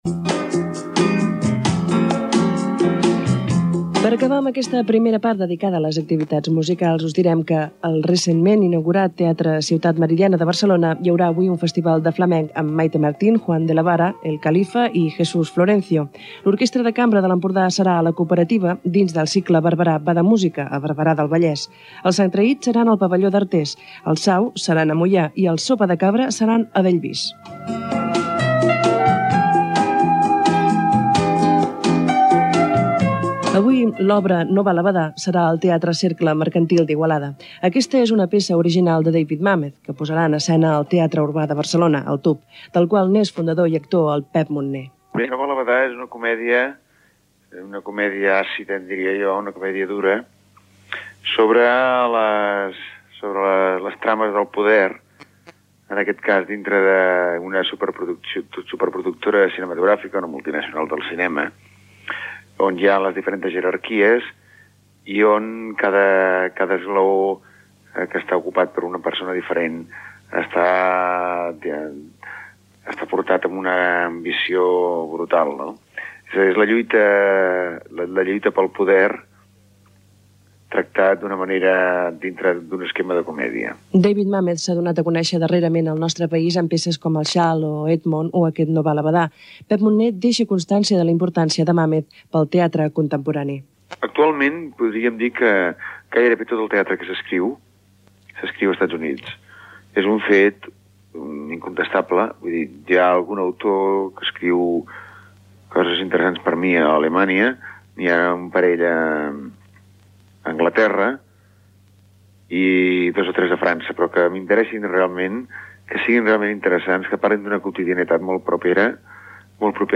Repàs a la cartellera musical i teatral catalana, amb declaracions dels actors Pep Munné i Pepe Rubianes, Careta de sortida.
FM